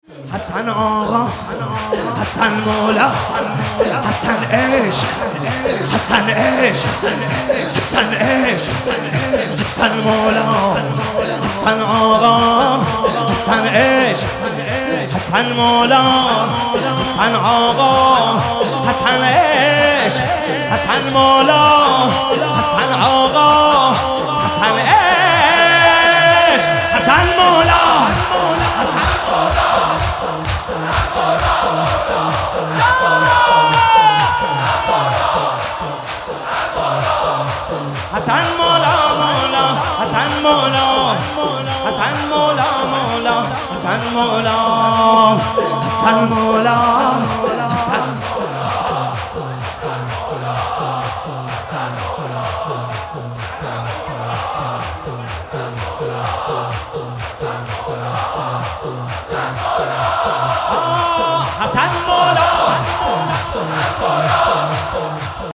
صوت/ شب ششم محرم با نوای حاج محمدرضا طاهری | نیوزین
مناجات روضه زمزمه زمینه نوحه واحد(حسین طاهری) واحد(حسین طاهری) شور(حسین طاهری) شور(حسین طاهری) شور(حسین طاهری) شور(حسین طاهری) شور(حسین طاهری) شور(حسین طاهری)